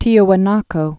(tēə-wə-näkō) or Ti·wa·na·ku (tēwə-näk)